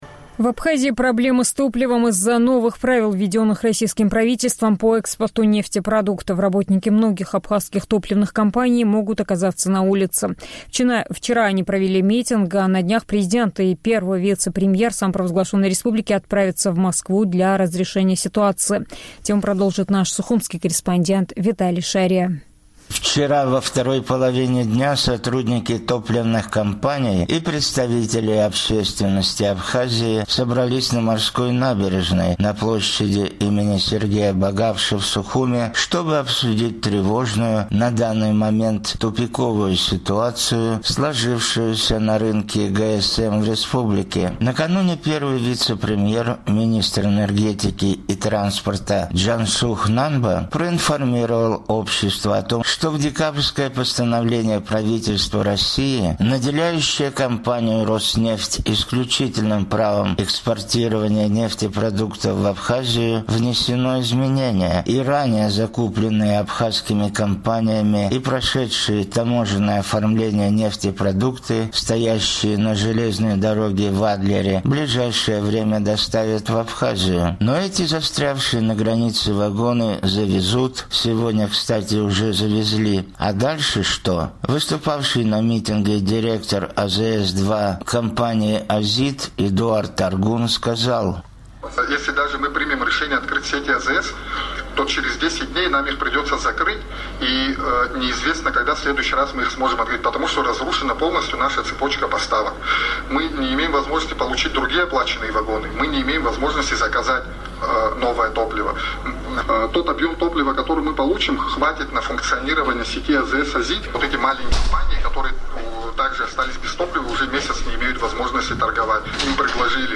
После окончания переговоров к митингующим спустился Джансух Нанба и обратился к ним: